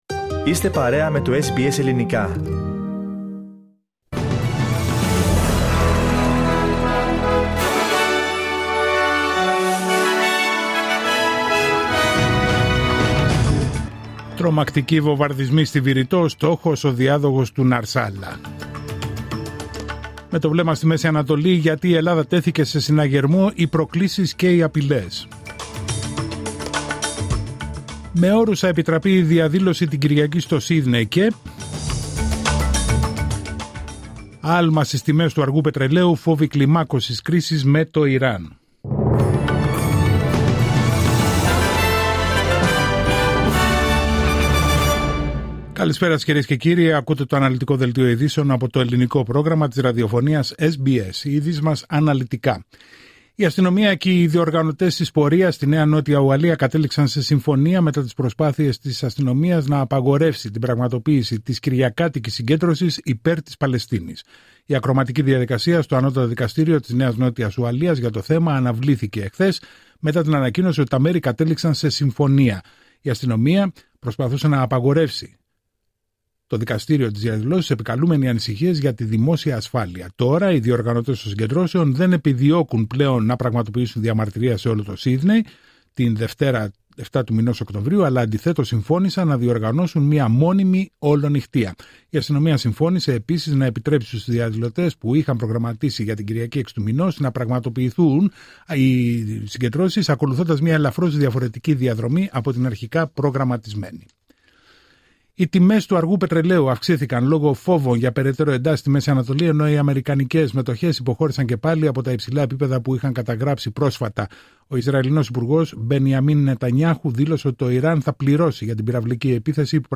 Δελτίο ειδήσεων Παρασκευή 4 Οκτωβρίου 2024